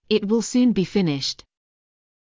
ｳｨｰ ｱｰ ﾆｱﾘｰ ﾌｨﾆｯｼｭﾄﾞ